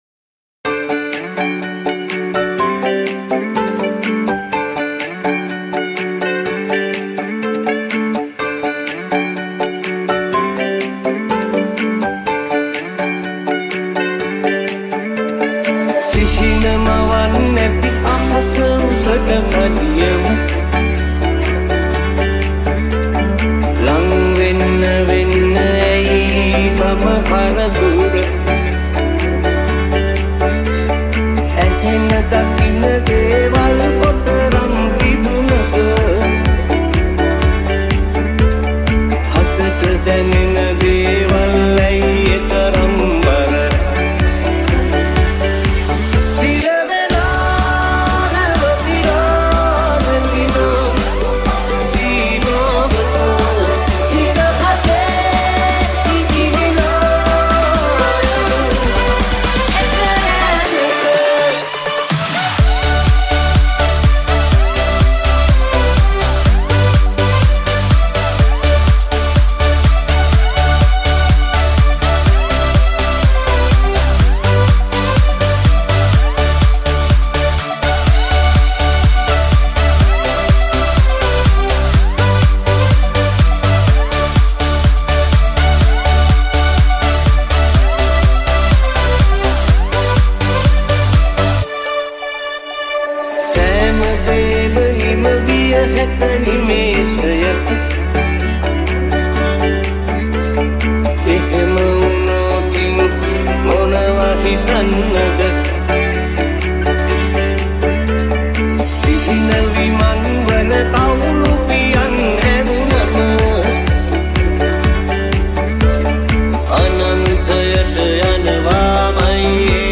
High quality Sri Lankan remix MP3 (2.8).
high quality remix